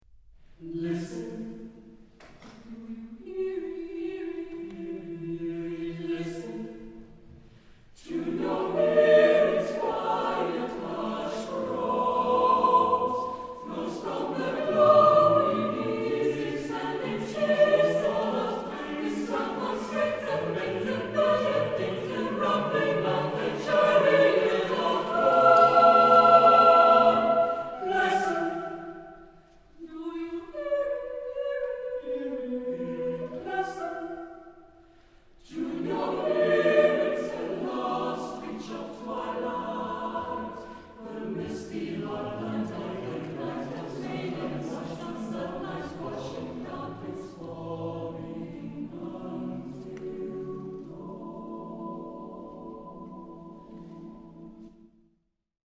Genre-Style-Form: Secular ; Ode
Mood of the piece: jazzy ; joyous ; ample
Type of Choir: SATB  (4 mixed voices )
Tonality: B flat major